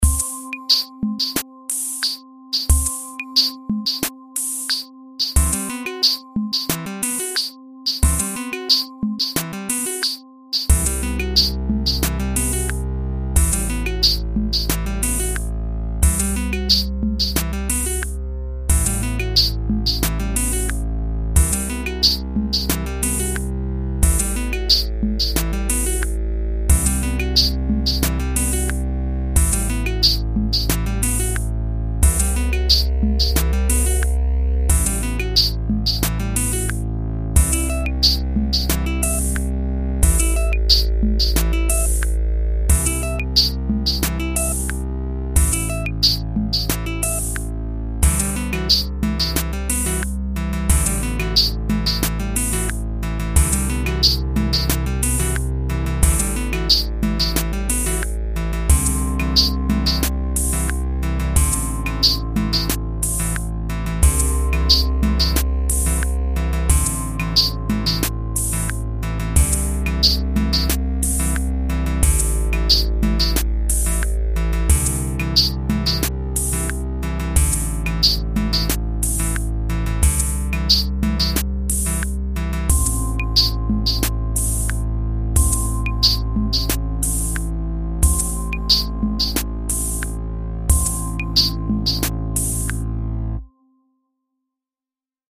instumental
MiniErHuという二胡のVSTを試験的に使用してみた。